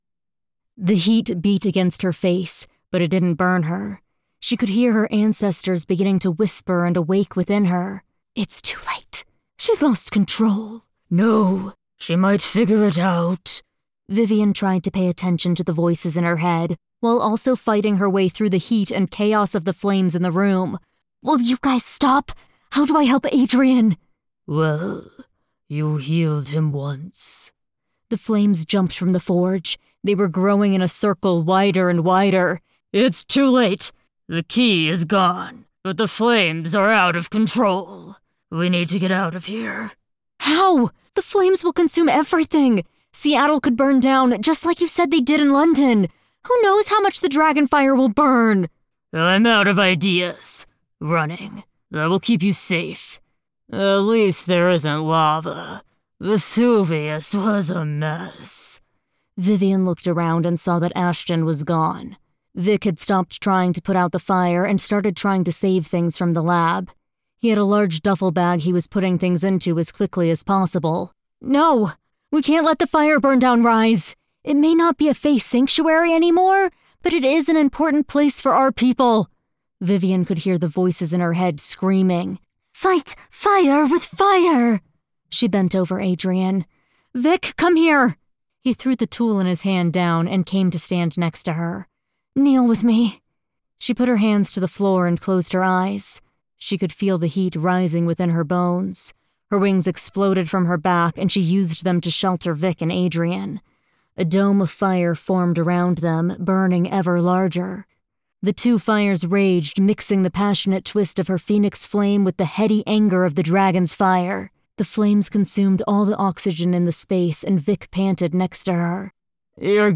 3rd Person YA Action